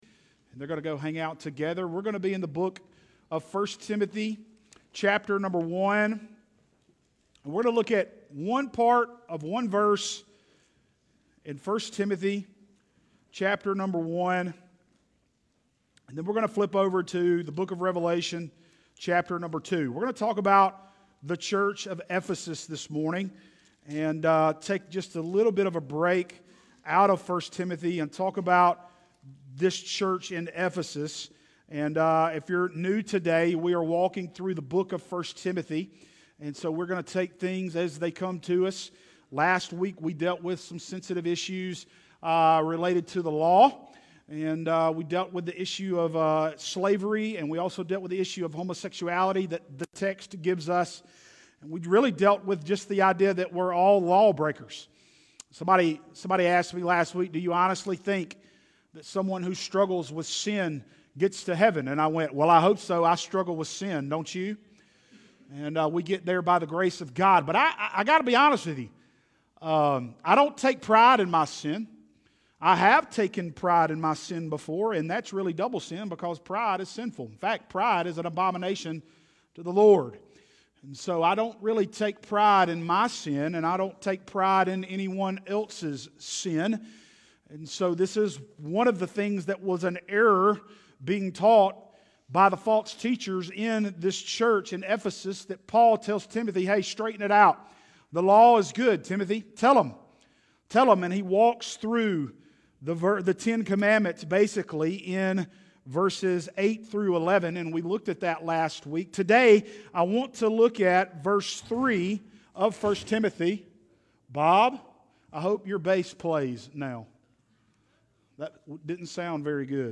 6-13-worship.mp3